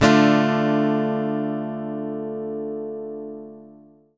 OVATION D-MO.wav